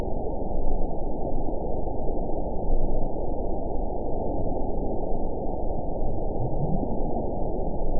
event 921535 date 11/26/24 time 01:16:37 GMT (5 months, 1 week ago) score 6.65 location TSS-AB02 detected by nrw target species NRW annotations +NRW Spectrogram: Frequency (kHz) vs. Time (s) audio not available .wav